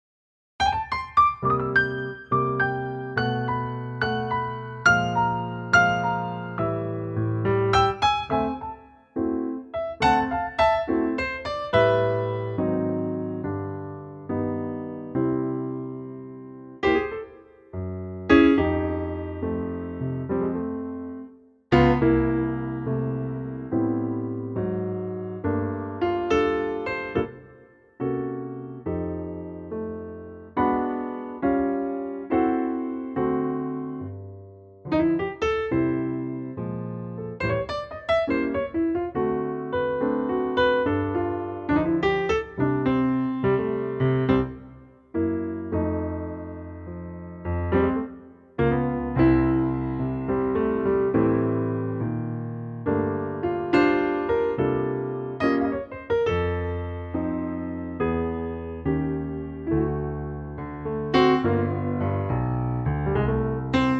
Unique Backing Tracks
key - C - vocal range - G to A
Wonderful piano only arrangement